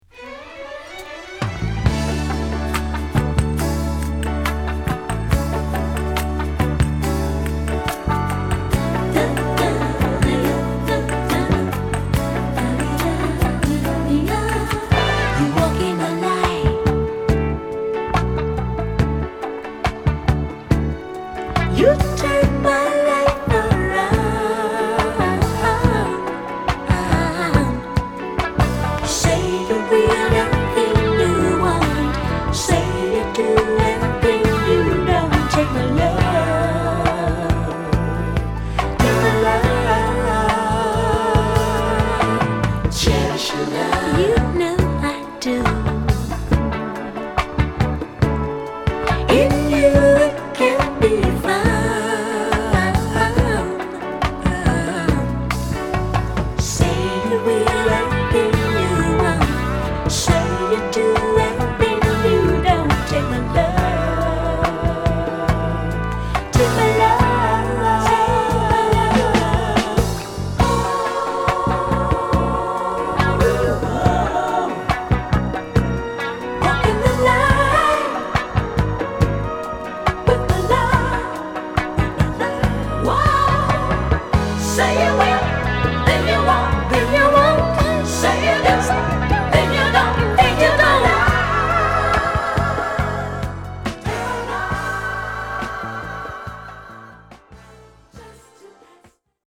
これからの季節にもハマる心地良い1曲。